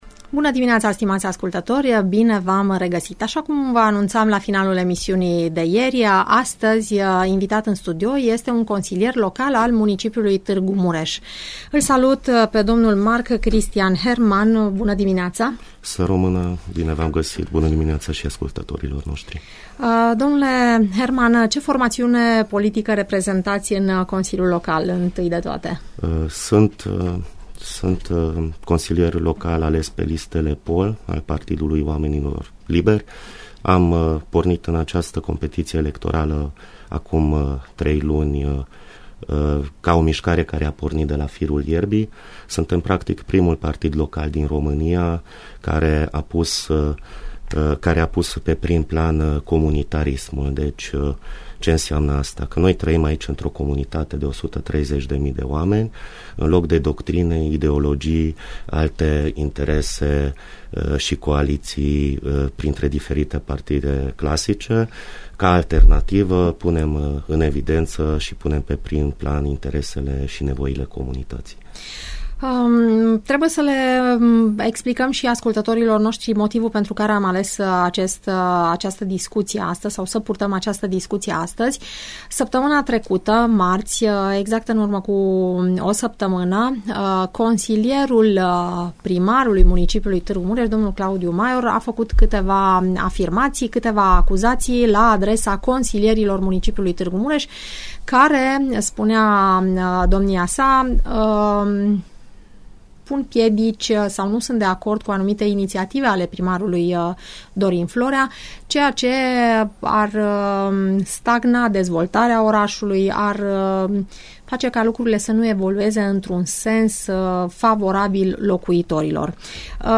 Consilierul local al municipiului Targu Mures domnul Mark Christian Hermann in direct la „Parerea ta”